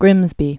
(grĭmzbē)